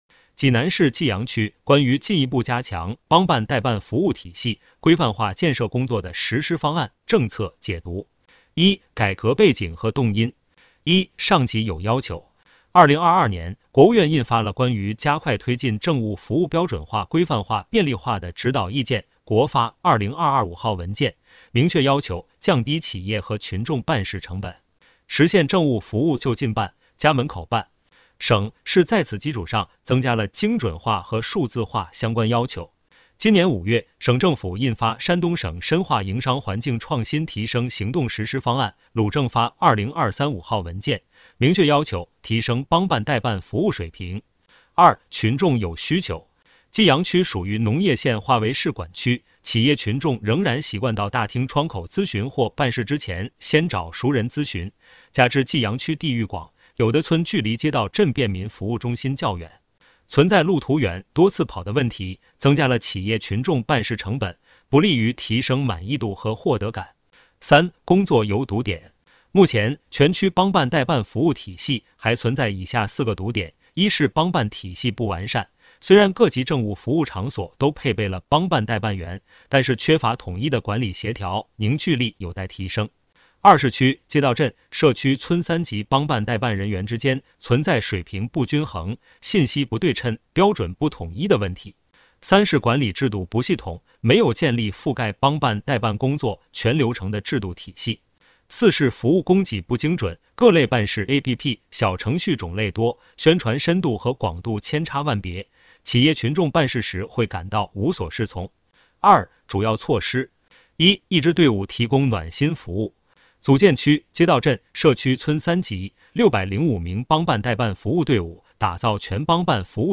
【有声朗读】《济南市济阳区人民政府办公室关于进一步加强帮办代办服务体系规范化建设工作的实施方案》政策解读.mp3